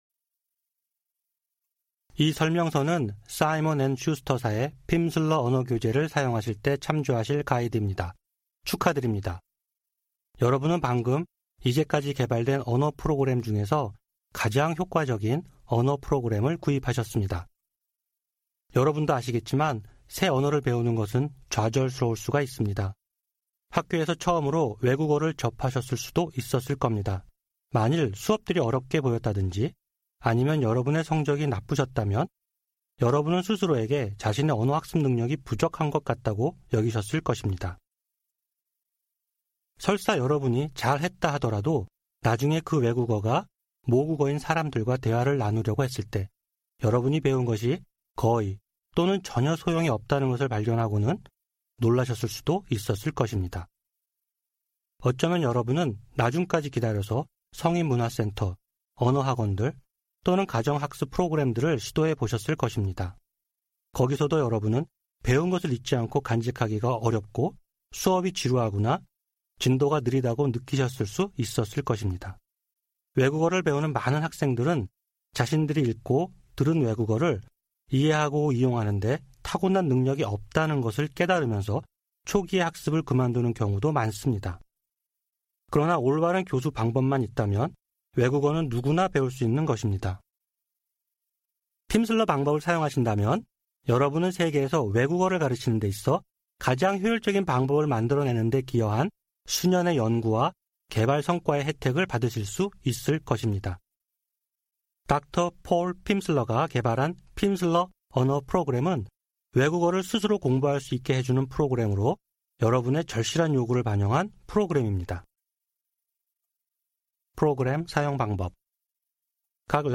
Narrated by: Pimsleur Language Programs
Audiobook
This course includes Lessons 1-5 from the English for Korean Speakers Level 1 30-Lesson Program featuring 2.5 hours of language instruction. Each lesson provides 30 minutes of spoken language practice, with an introductory conversation, and new vocabulary and structures.